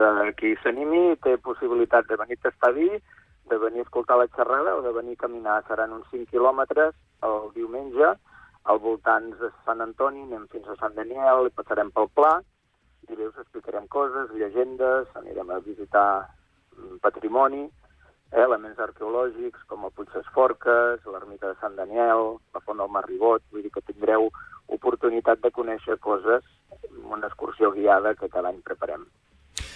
En una entrevista concedida al programa Supermatí